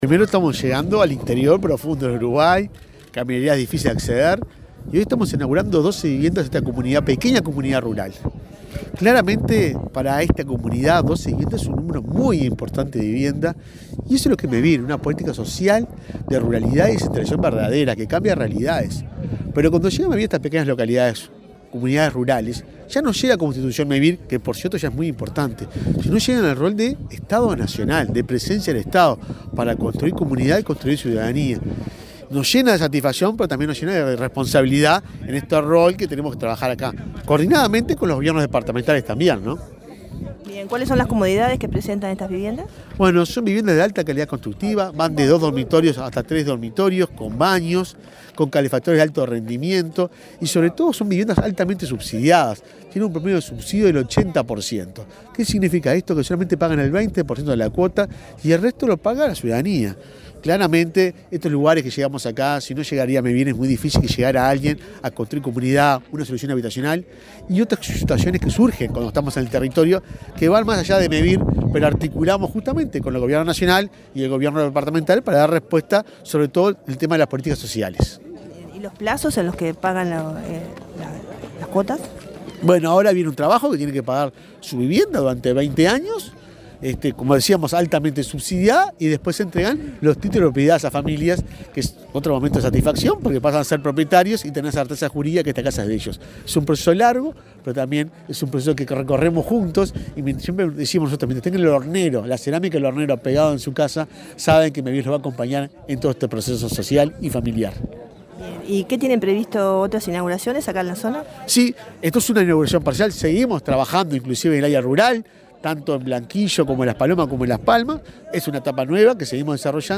Entrevista al presidente de Mevir